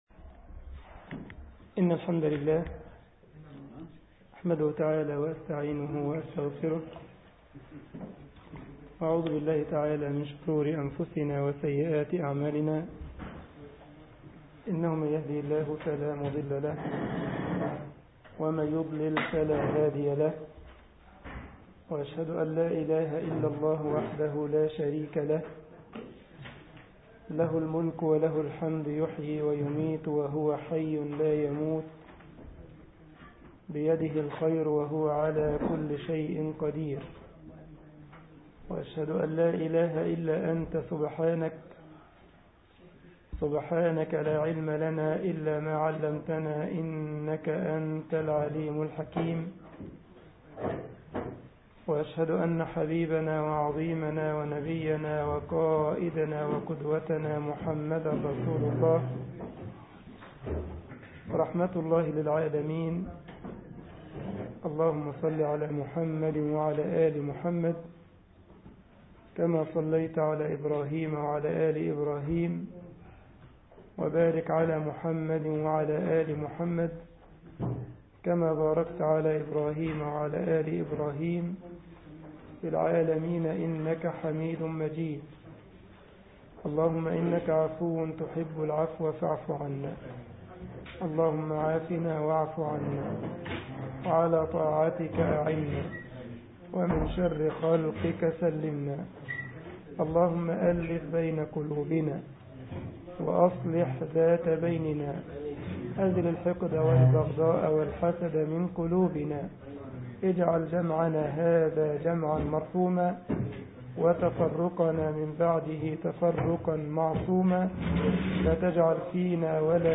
الجمعية الإسلامية بالسارلند ـ ألمانيا درس